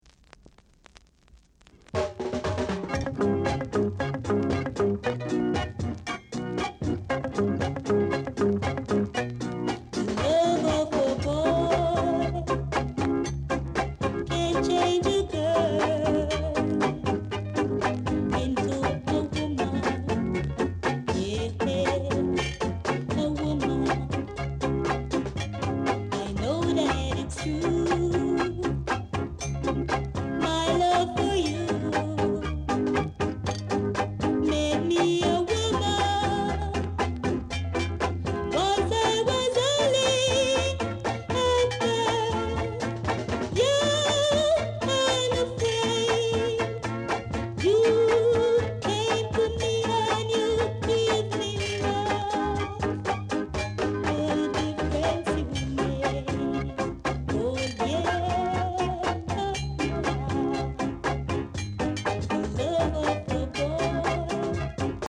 7inch
Regae Female Vocal